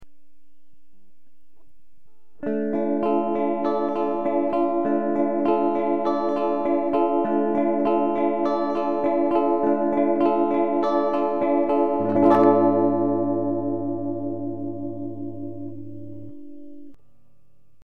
Guitar 1 sounds like this playing the sequence on the Dm only..